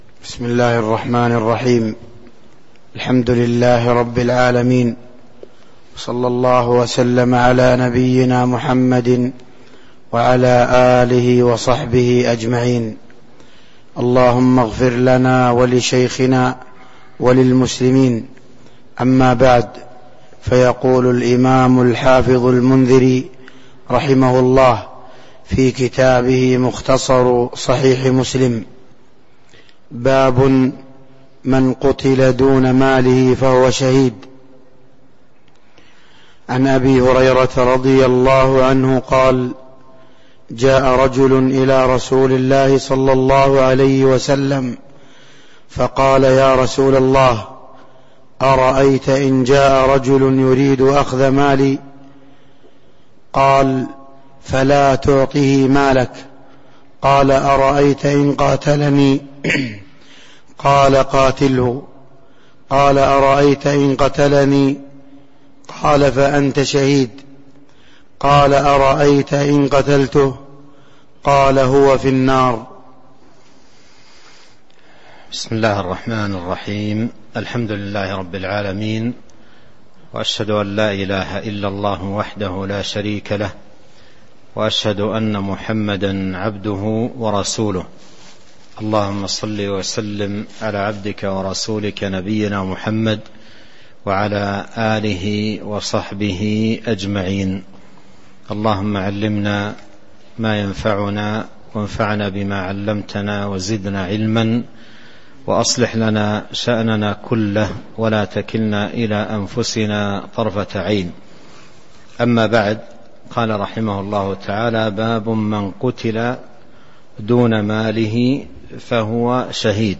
تاريخ النشر ٢٦ ربيع الأول ١٤٤٣ هـ المكان: المسجد النبوي الشيخ: فضيلة الشيخ عبد الرزاق بن عبد المحسن البدر فضيلة الشيخ عبد الرزاق بن عبد المحسن البدر باب من قتل دون ماله فهو شهيد (06)